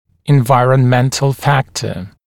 [ɪnˌvaɪərən’mentl ‘fæktə] [инˌвайэрэн’мэнтл ‘фэктэ] фактор окружающей среды